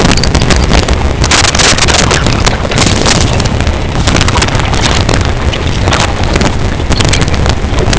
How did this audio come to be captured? Hello, I am using a NRF5340 with an IM69D120 mic. Our requirement is a 512 kHz clock with a sampling frequency of 4 kHz and a decimination ratio of 128. If not, how can I set the ratio in this register, NRF_PDM0->RATIO? ie:128 3)I have attached distorted audio file Play this audio clip kindly check.